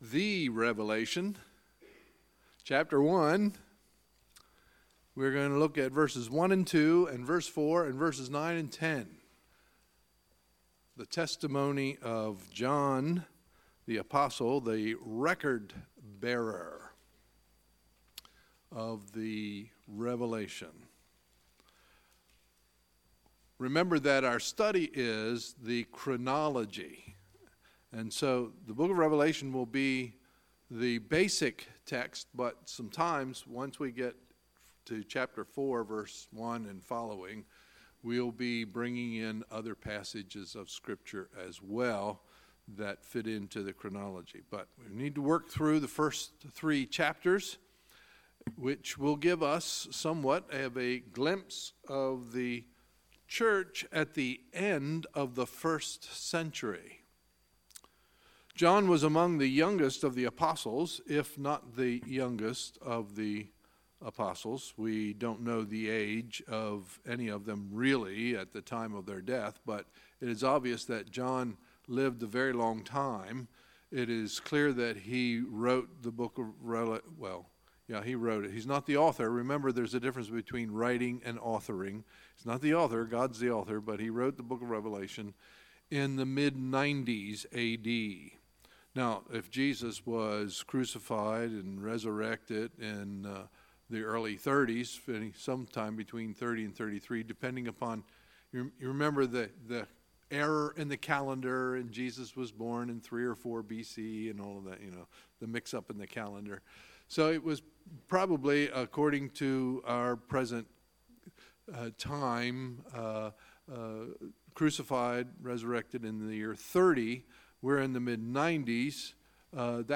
Sunday, January 21, 2018 – Sunday Evening Service